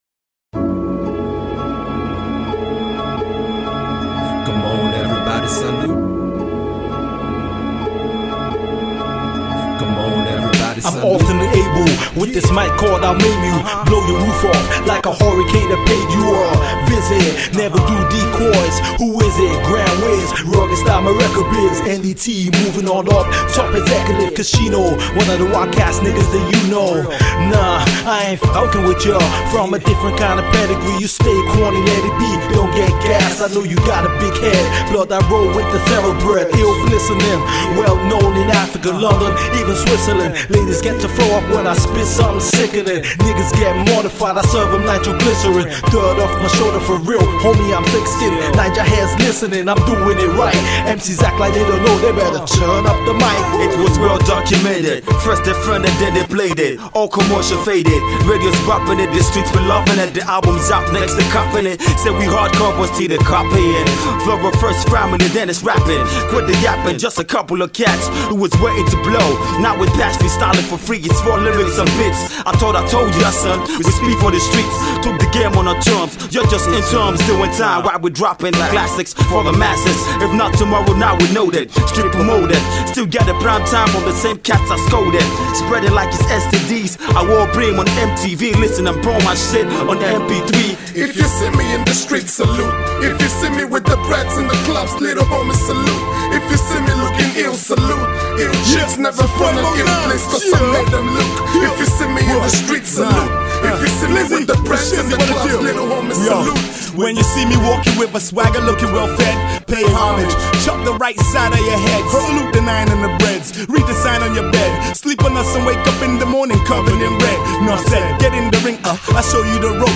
Hip-Hop
a Posse song
Its a Hardcore song that would definitely satisfy Rap heads